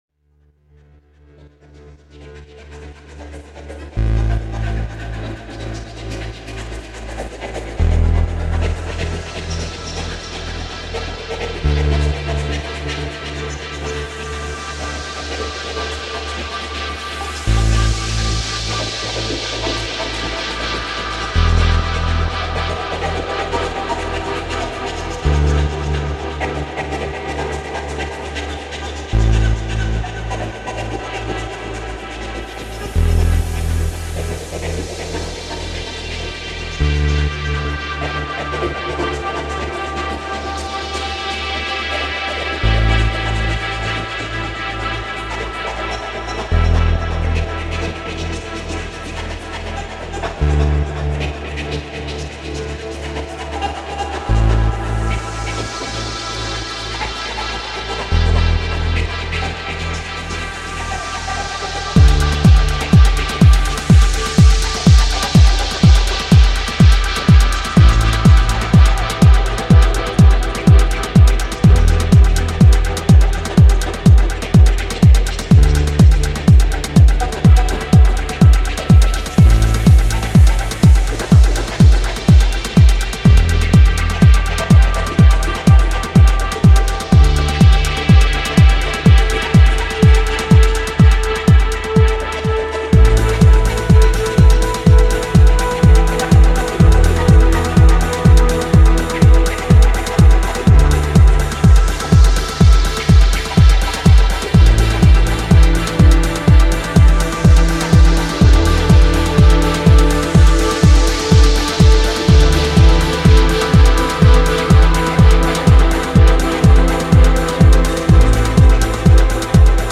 Houston protest reimagined